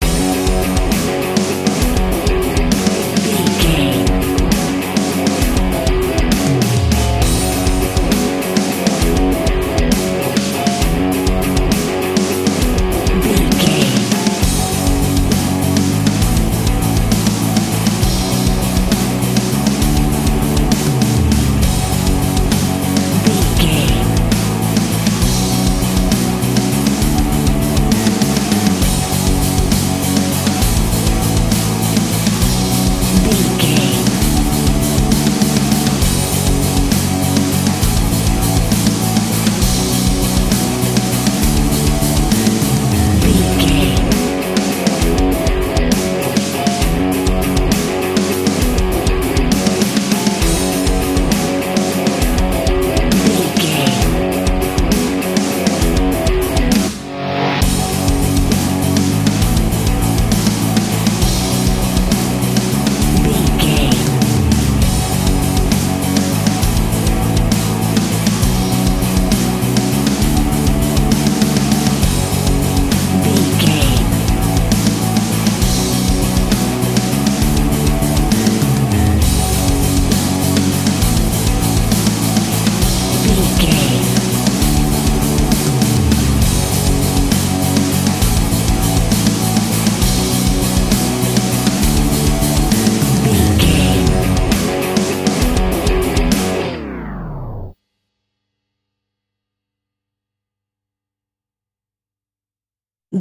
Ionian/Major
hard rock
lead guitar
bass
drums
aggressive
energetic
intense
nu metal
alternative metal